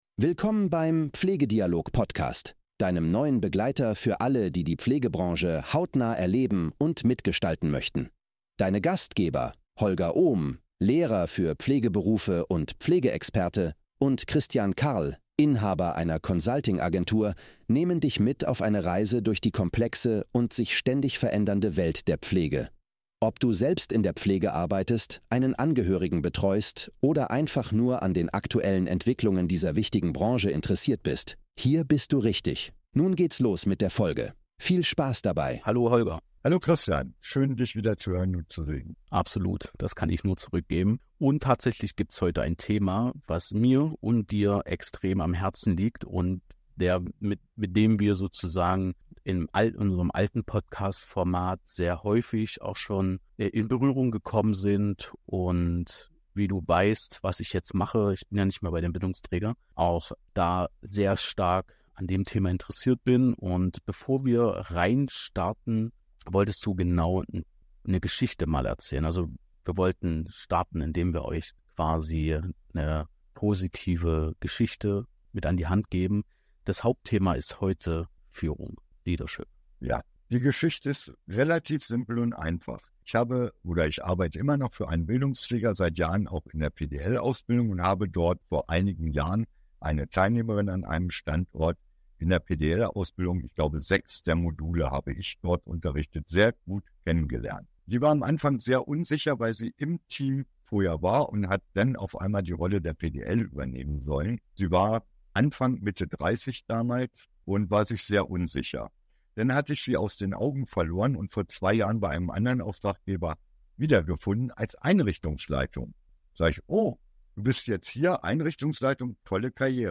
In dieser Episode sprechen wir mit einer ehemaligen Teilnehmerin einer PDL-Ausbildung, die sich zur Einrichtungsleitung entwickelt hat. Sie teilt ihre Reise von anfänglicher Unsicherheit hin zu einem erfolgreichen Führungsstil, der auf die Bedürfnisse ihrer Mitarbeiter eingeht.